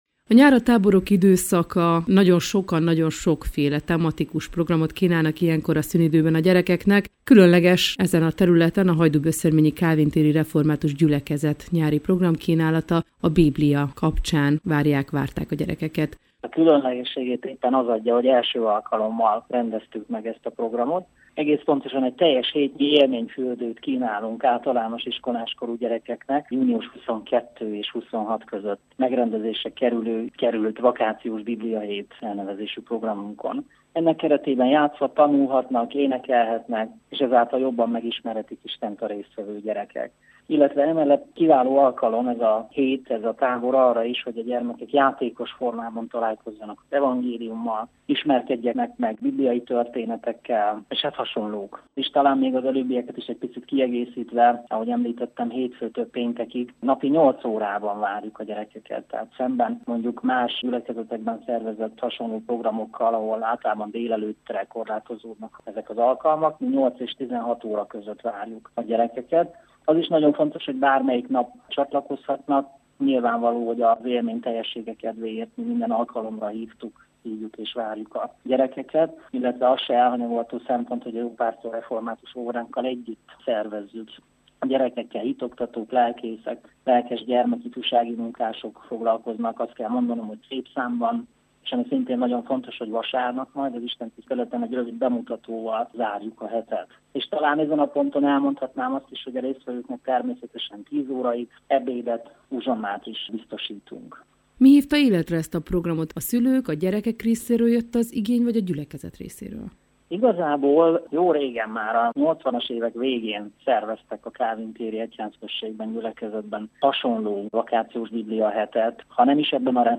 Az Európa Rádióban elhangzott beszélgetést itt hallgathatják meg: